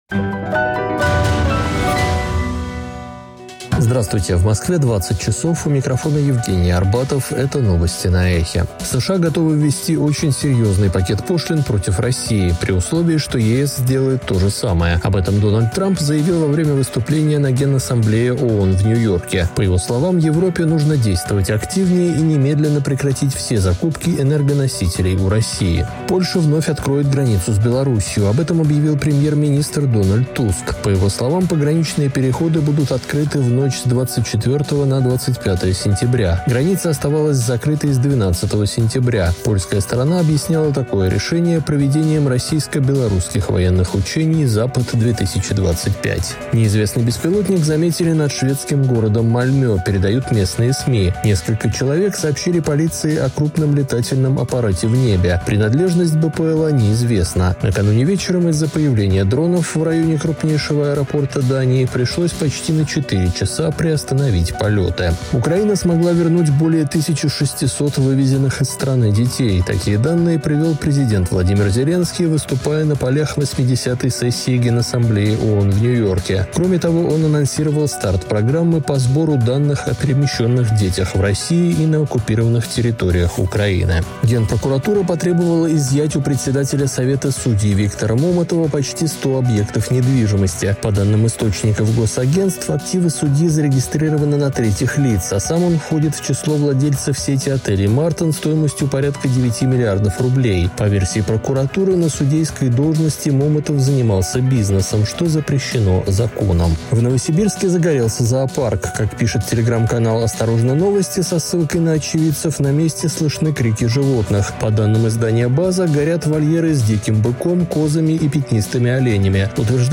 Слушайте свежий выпуск новостей «Эха»
новости 20:00